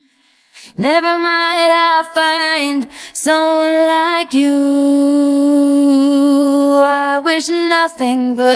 audio-to-audio singing-voice-conversion voice-cloning
Amphion Singing Voice Conversion: DiffWaveNetSVC